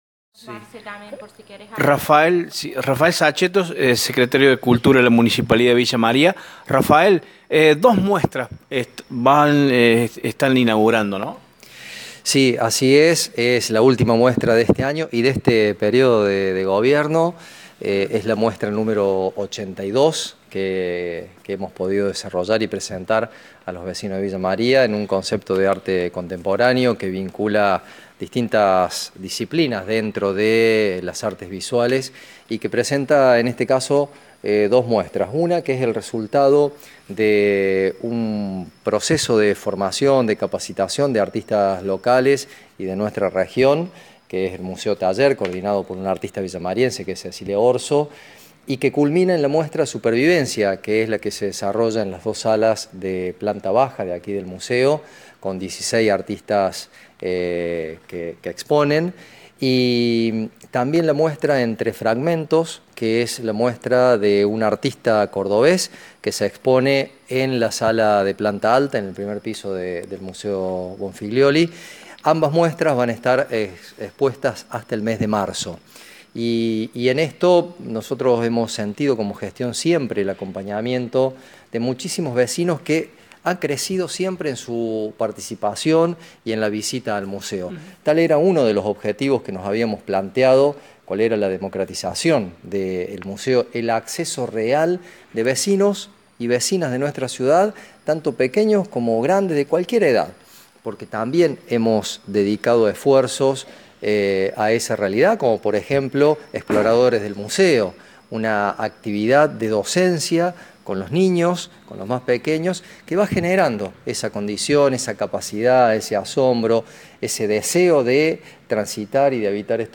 En una conversación previa con nuestro medio, Sachetto proporcionó detalles sobre ambos eventos, destacando la diversidad artística que se podrá apreciar en estas exposiciones.